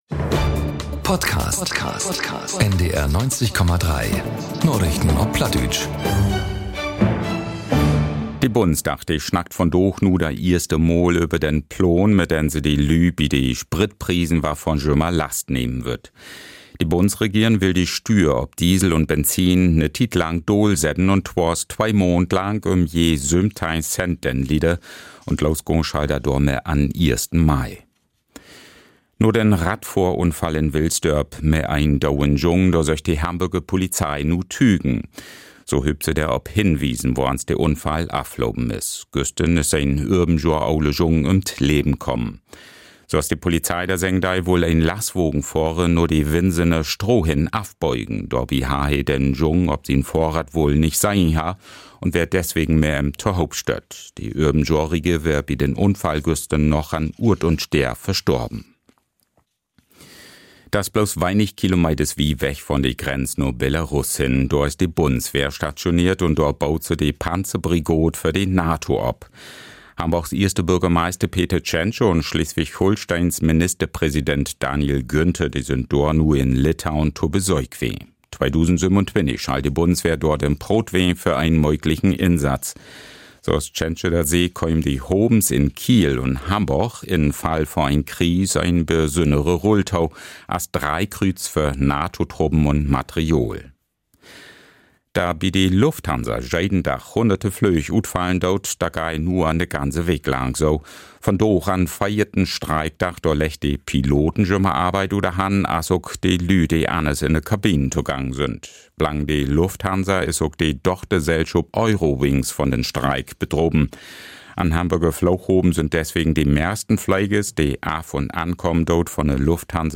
Narichten op Platt 16.04.2026 ~ Narichten op Platt - Plattdeutsche Nachrichten Podcast